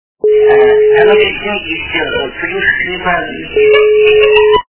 » Звуки » Из фильмов и телепередач » Малыш и Карлсон - А мы тут, знаете, плюшками балуемся, хи-хи-хи...
При прослушивании Малыш и Карлсон - А мы тут, знаете, плюшками балуемся, хи-хи-хи... качество понижено и присутствуют гудки.